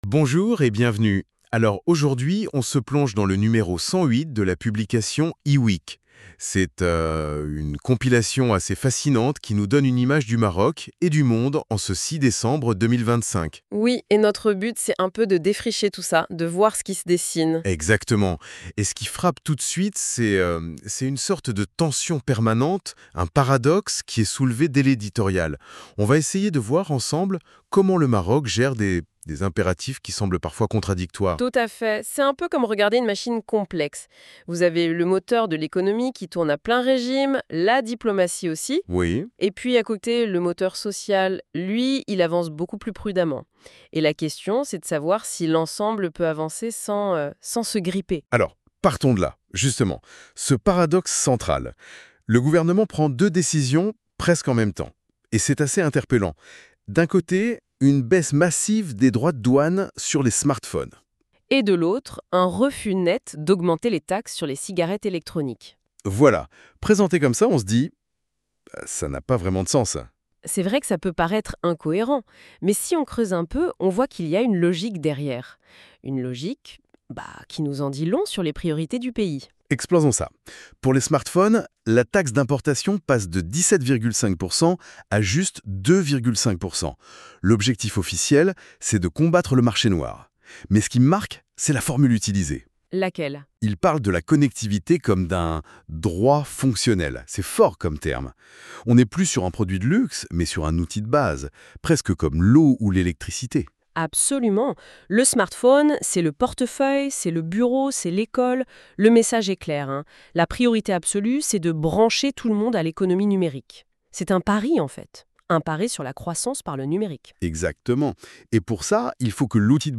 Débat en Podcast de la Web Radio R212 | Téléchargements | L'Opinion DJ Gen X,Y et Z
Les débats en podcast des chroniqueurs de la Web Radio R212 débattent de différents sujets d'actualité